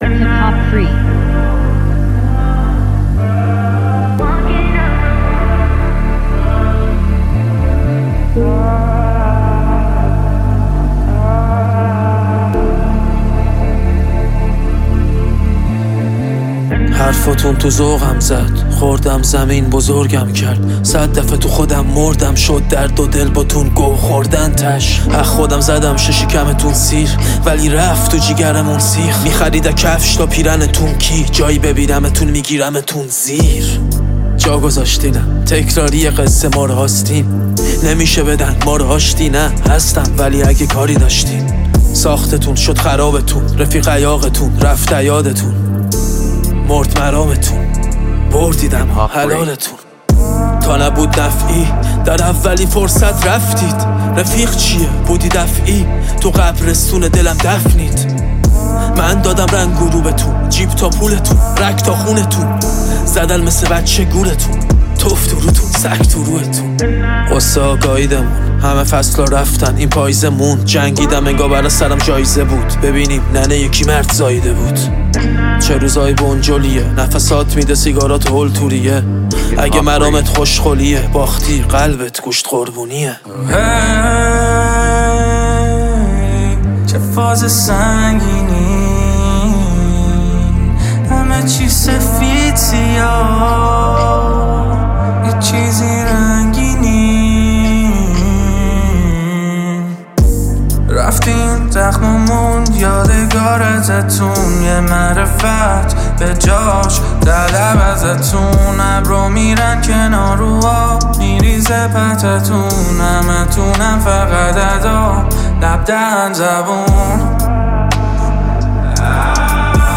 رپ